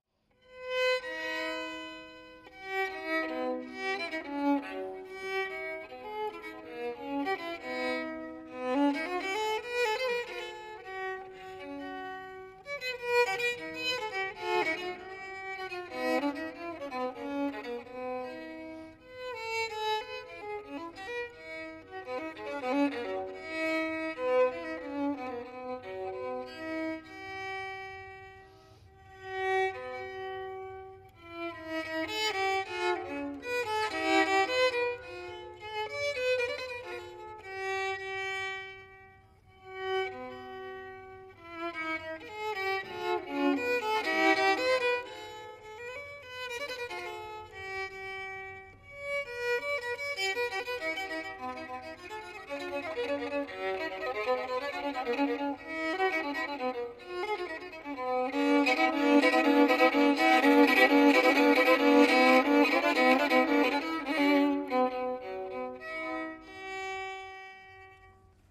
Violins
Live at St Mary at Hill, London 16th January 2018
This Allemande uses an extremely constricted returning-covering just an octave from low G. In order to stablise the instrument to play this turning, it is strung with thick gut strings, and I reversed the position of the anchos behind the bridge and in the pegbox  thus:
Allemandscord.mp3